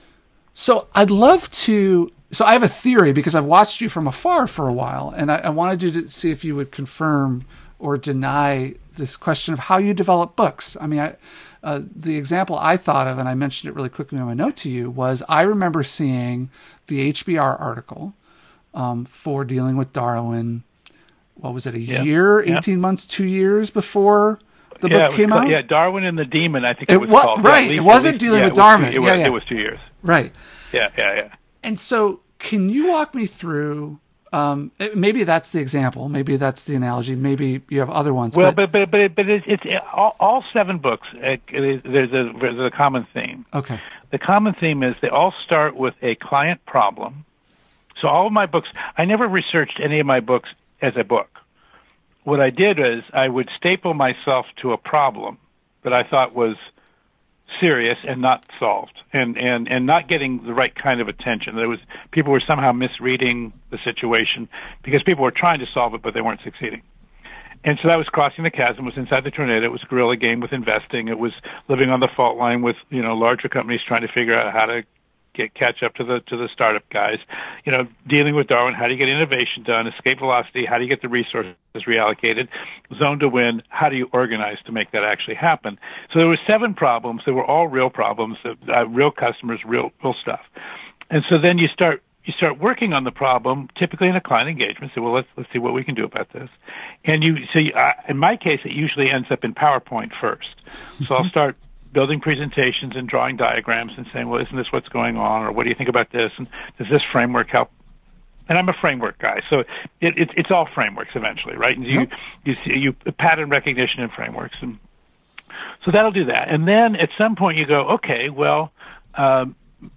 Listen to this seven minute clip of an interview I did with Geoffrey Moore.
Geoffery-Moore-Interview-Staple-Yourself-to-The-Problem-Excerpt.m4a